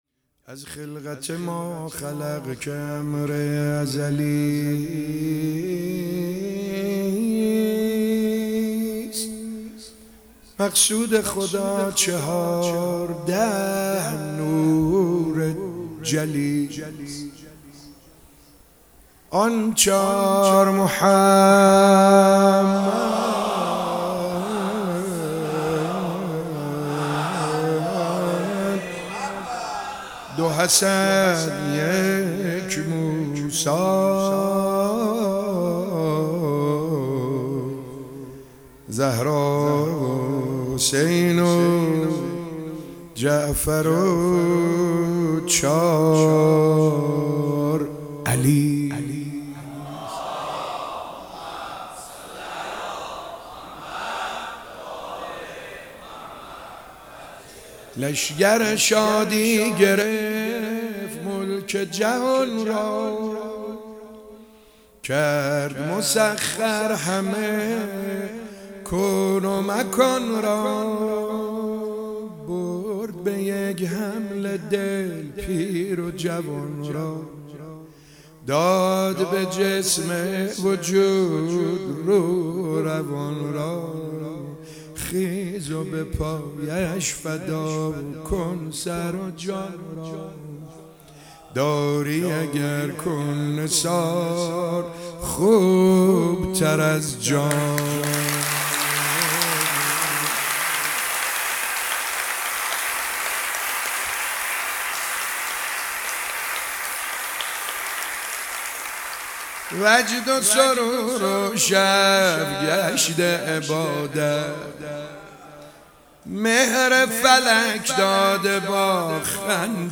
مولودی‌خوانی